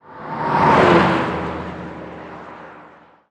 Highway / oldcar / car5.wav
car5.wav